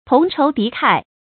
成語注音ㄊㄨㄙˊ ㄔㄡˊ ㄉㄧˊ ㄎㄞˋ
成語拼音tóng chóu dí kài
發音讀音
同仇敵愾發音